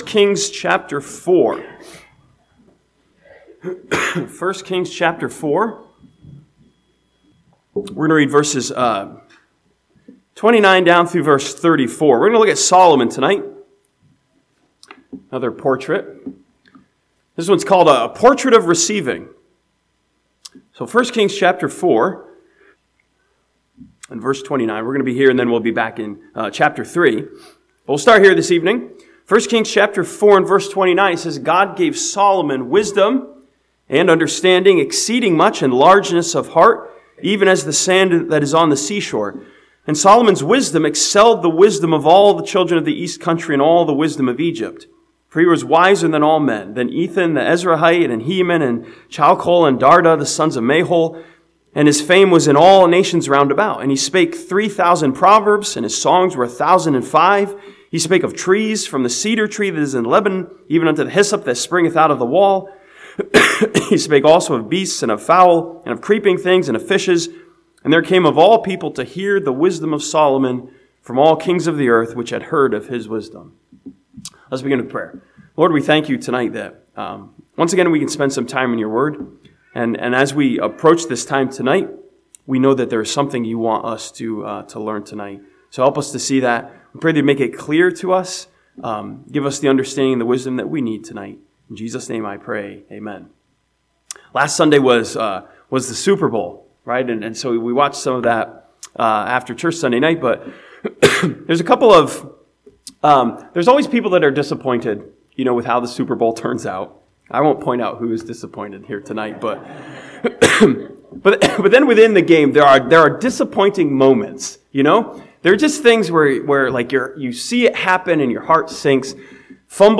This sermon from 1 Kings chapter 4 challenges us with Solomon as a portrait of receiving and challenges us to see our neediness.